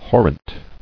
[hor·rent]